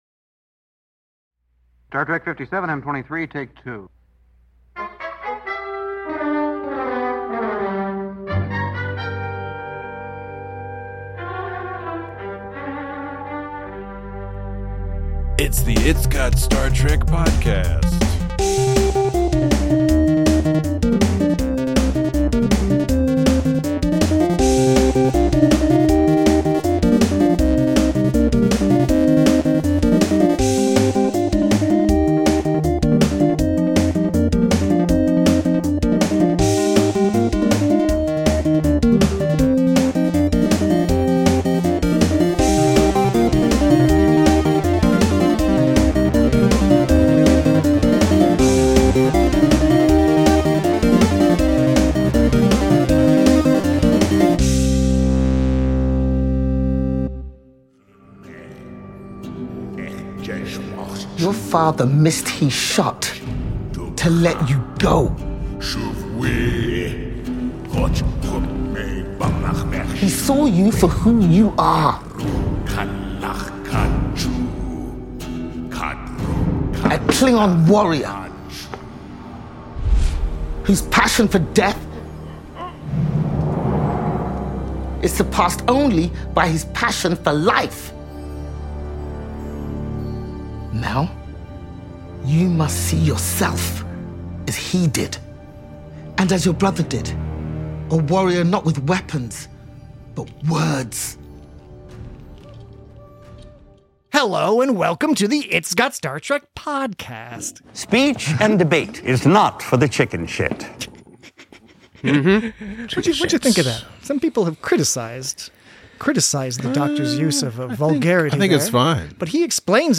We dive right into a detail-rich discussion of this episode of Star Trek: Starfleet Academy, so if you haven’t had a chance to see it yet – beware!